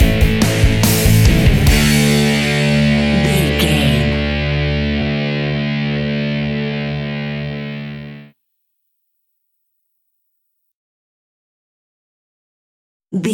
Epic / Action
Fast paced
Ionian/Major
D
hard rock
distortion
instrumentals
rock guitars
Rock Bass
Rock Drums
heavy drums
distorted guitars
hammond organ